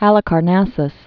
(hălĭ-kär-năsəs)